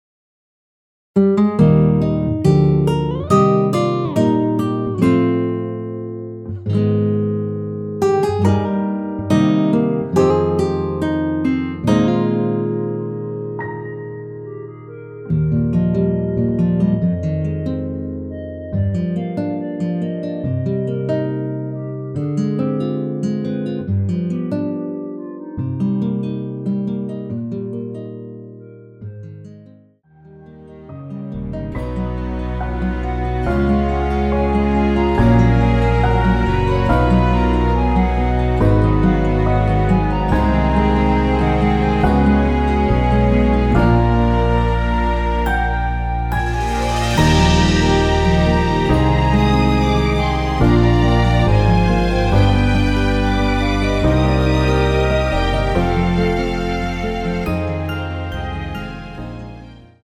원키에서(-2)내린 멜로디 포함된 MR입니다.(미리듣기 확인)
Eb
앞부분30초, 뒷부분30초씩 편집해서 올려 드리고 있습니다.